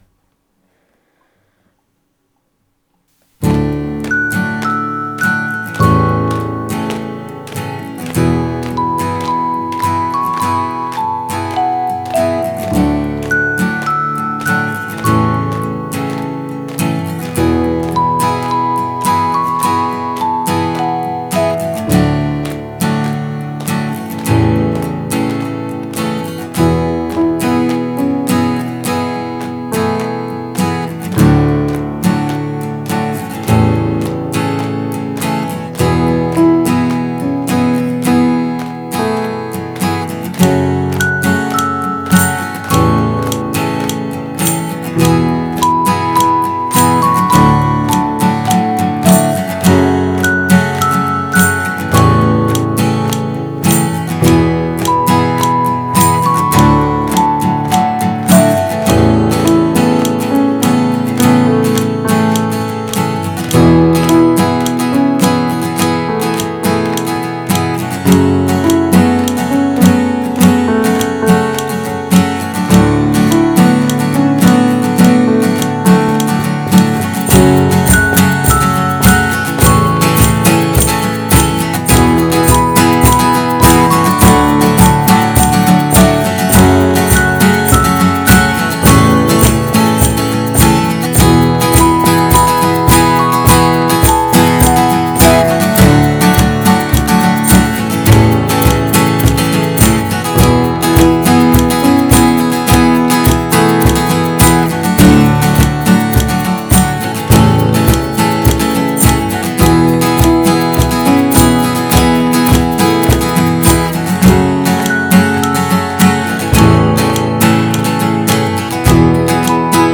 126 просмотров 155 прослушиваний 4 скачивания BPM: 104